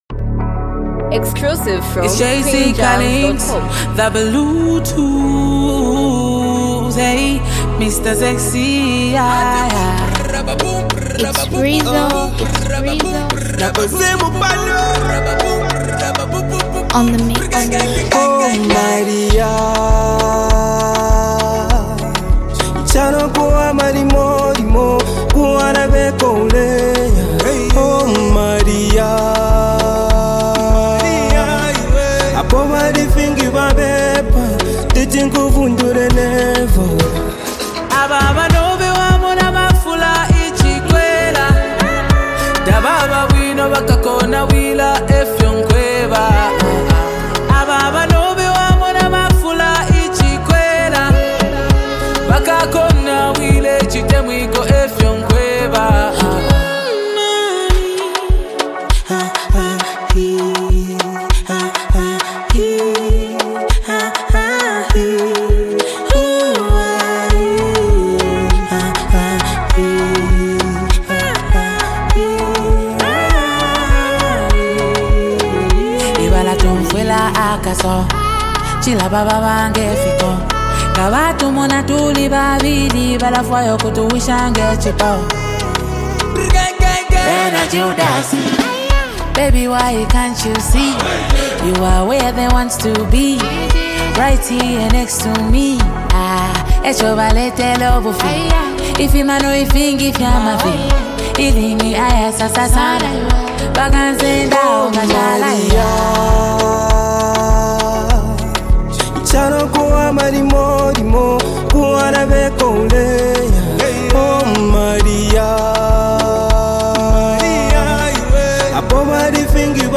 heartfelt love song
smooth Zambian Afro-pop melodies